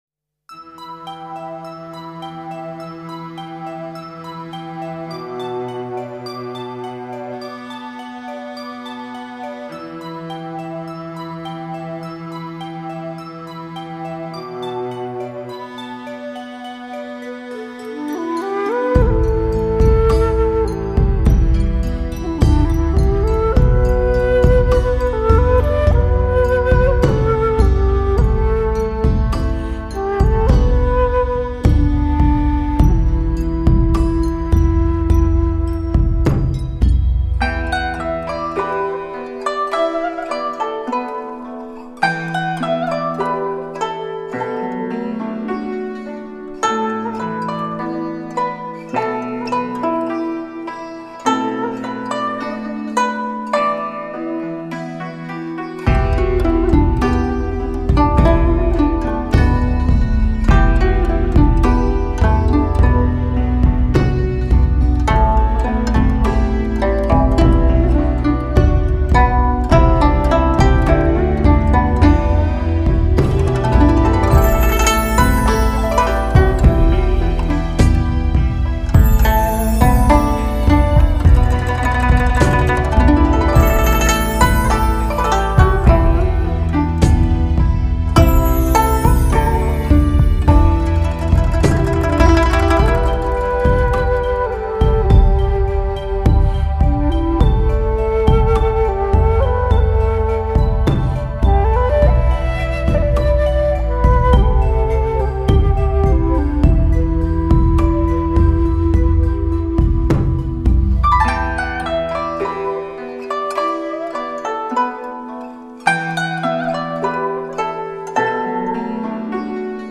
[纯音乐]
在“东风西渐”的潮流中，古筝以唯美、空灵、婉约的气质体现禅悦中的“空性、从容、淡定”；
笛子以自在、洒脱、灵动之美演绎登峰造极的复古情怀！最终达到“人乐合一”的超然境界！